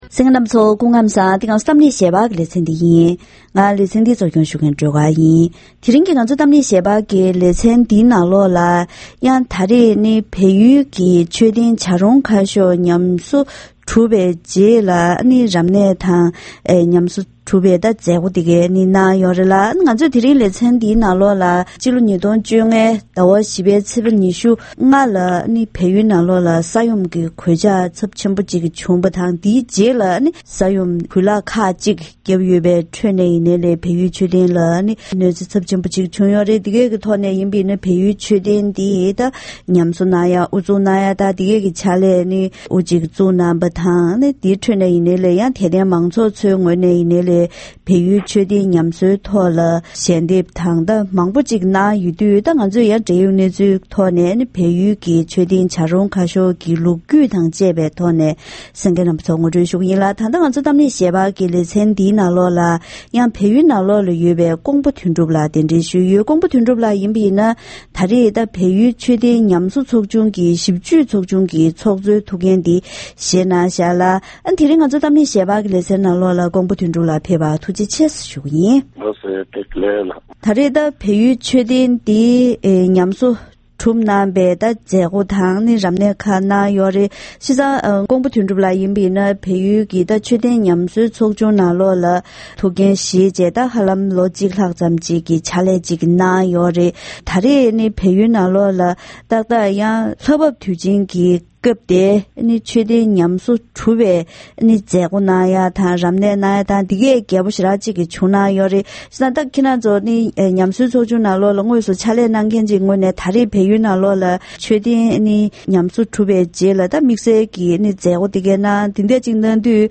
འབྲེལ་ཡོད་དང་ལྷན་ཉམས་གསོ་དང་ལོ་རྒྱུས་ཀྱི་སྐོར་བཀའ་མོལ་ཞུས་པ་ཞིག་གསན་རོགས་གནང་།